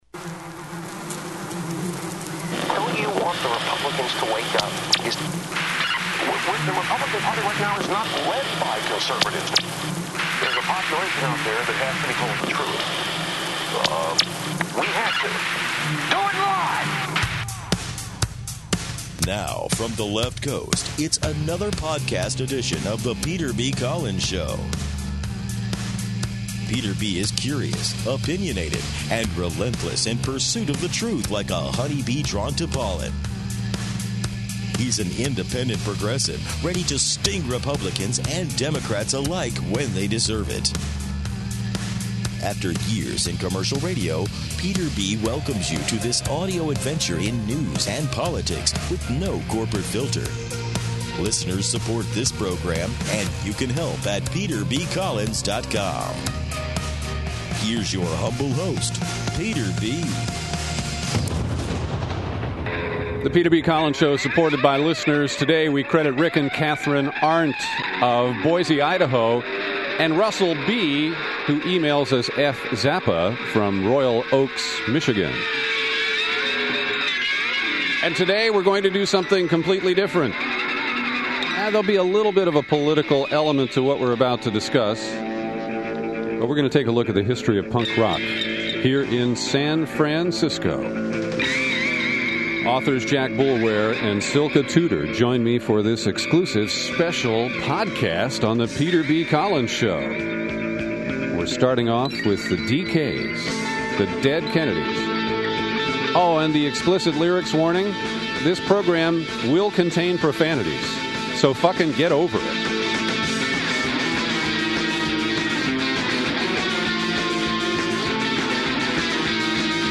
The no-holds-barred conversation includes explicit language, as do some of the songs you will hear.